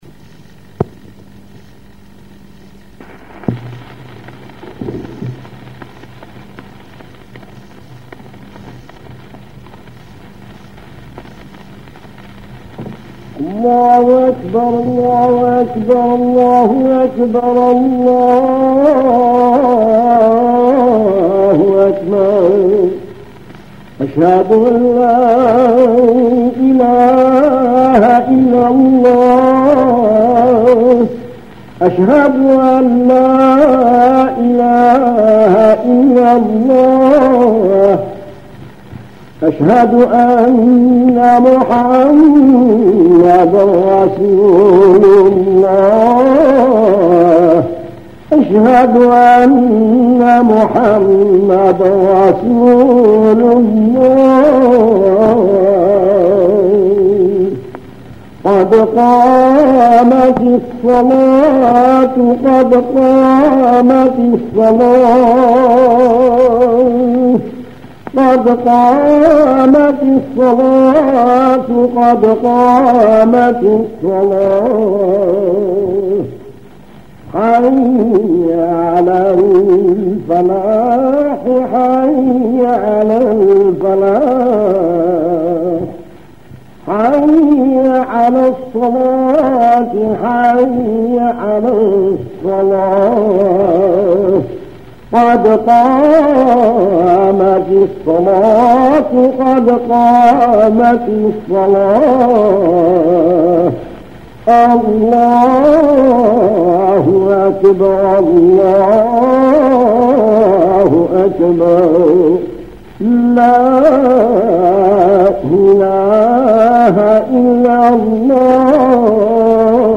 La Pratique de l'ADHAN
A Alger, les appels à la prière se faisaient presque tous sur le mode Zidane (quelle que soit la prière : Fadjr, Dôhr ou même Îch’a).
L’annonce du début de la prière peut se faire sur n’importe quel mode, tout dépend de l’humeur et de l’état d’âme de la personne.
iqama_01.mp3